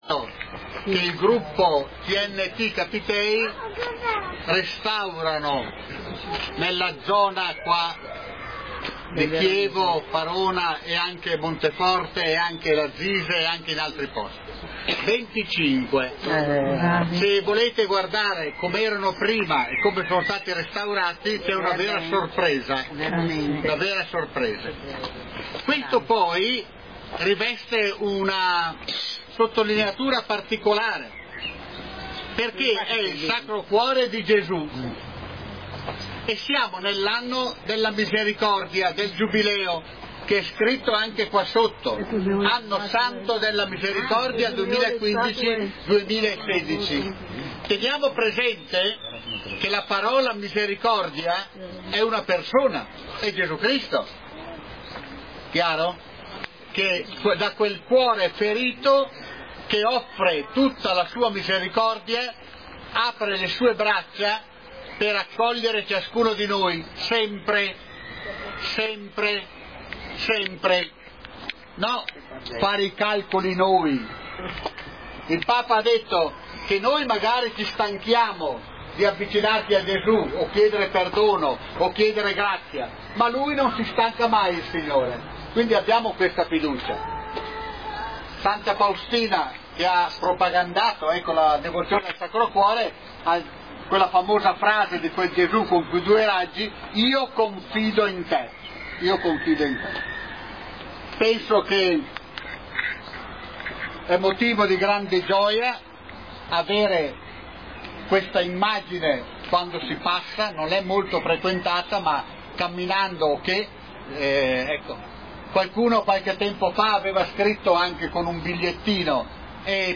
tradizionale benedizione. Alla cerimonia sono intervenute anche alcune autorità comunali.